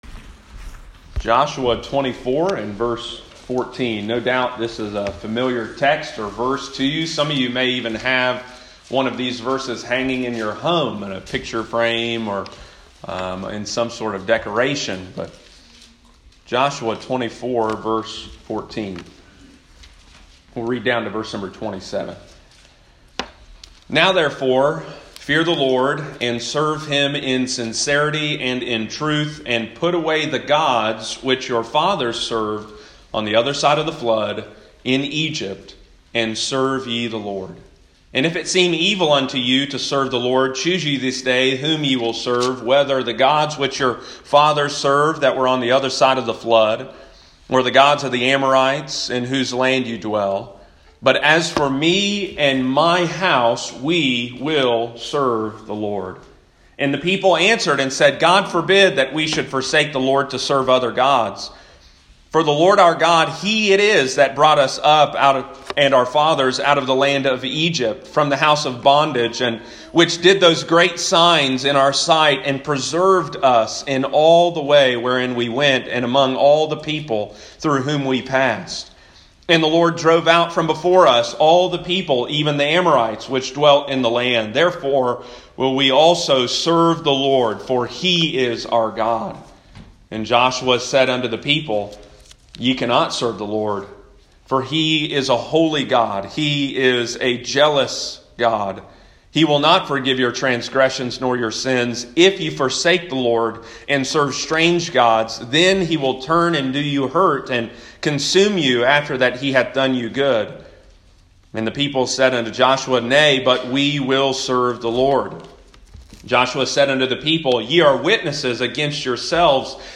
We are all worshippers, we will worship something, the question is what or who will we worship as a family? Sunday morning, November 29, 2020.